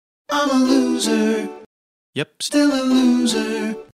Звуки лузеров
На этой странице собраны забавные звуки лузеров – от провальных фраз до эпичных неудач.